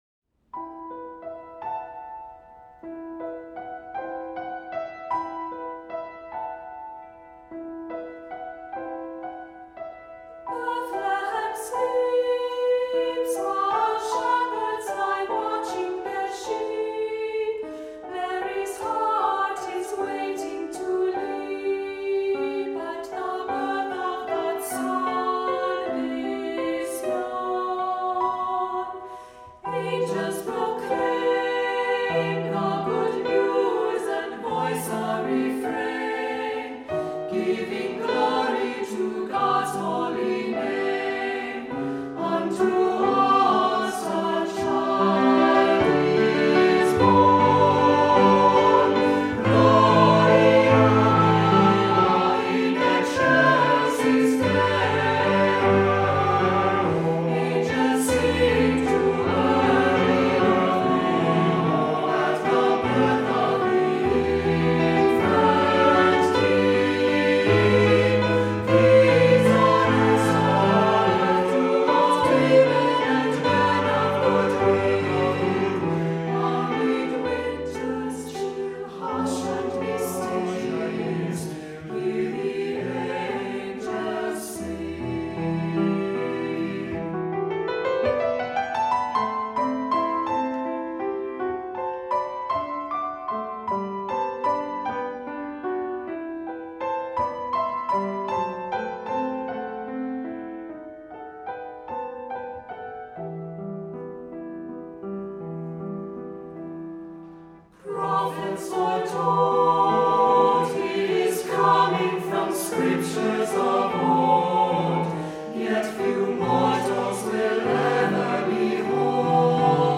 Voicing: Descant,Assembly,SAB